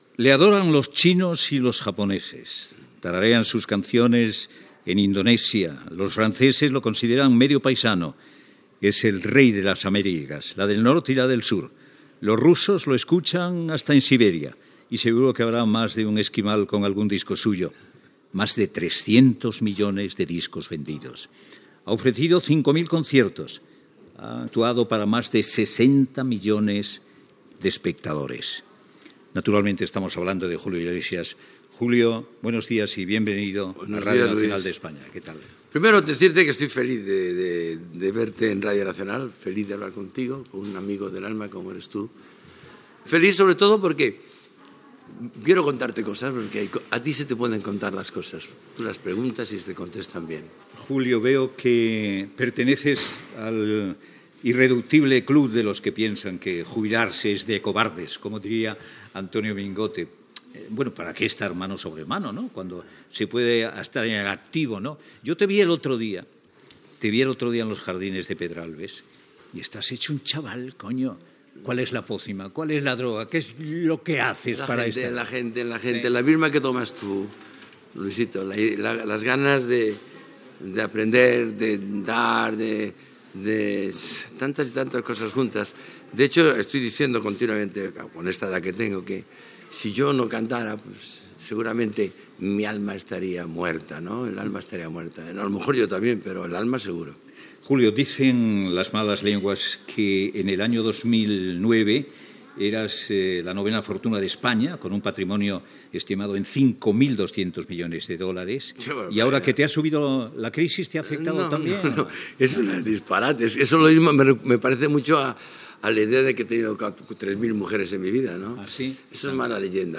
Entrevista al cantant Julio Iglesias, com mantenir la il·lusió, el seu patrimoni, com fer negocis, com paga els impostos, la llengua catalana, les decisions polítiques, la religió, Internet